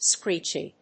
/skríːtʃi(米国英語)/